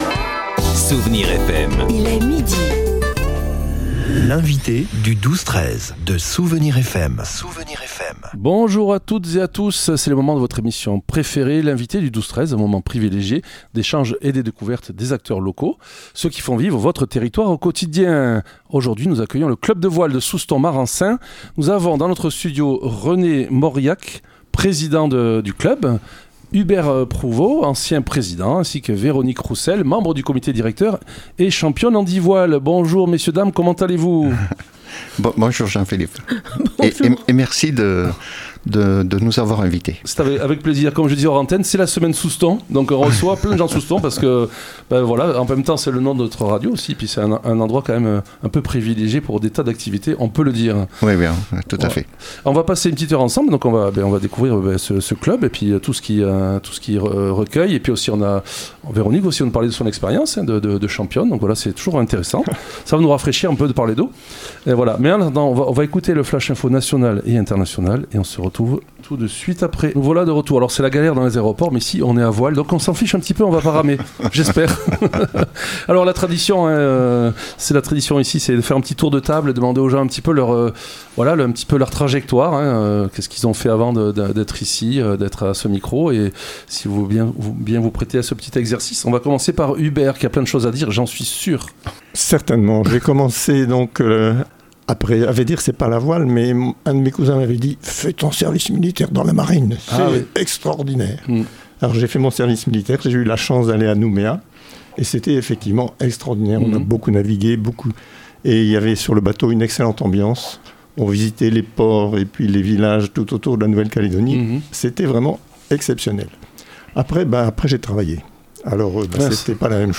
L'invité(e) du 12-13 de Soustons recevait aujourd'hui le Club De Voile de Soustons et Marensin.